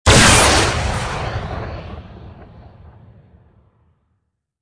fire_rocket2.wav